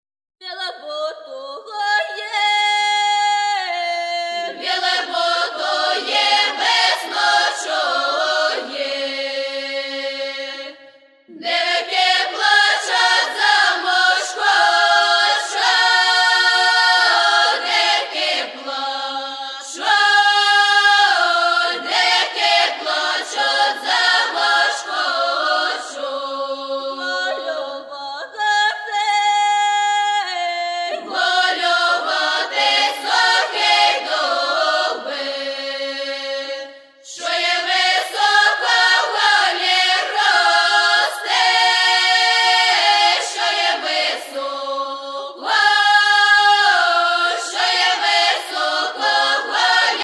Traditional Music of Rivne Region's Polissya